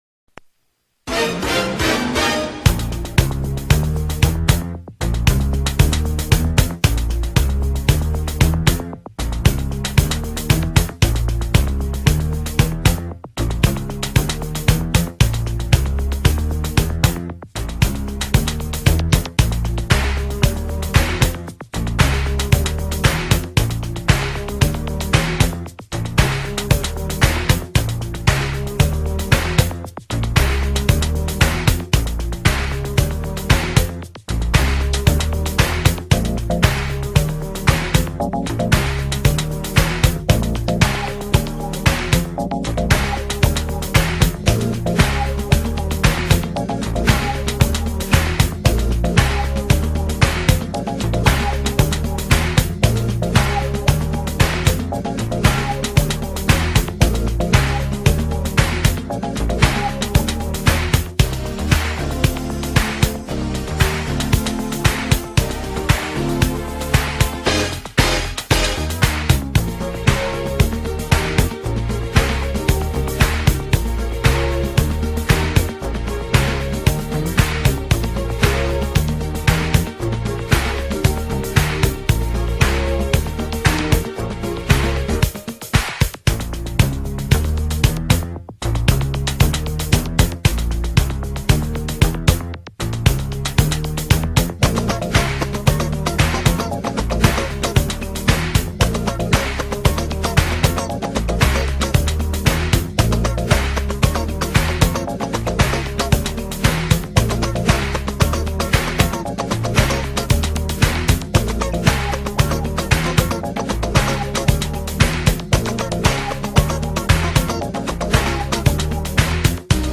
El acompañamiento: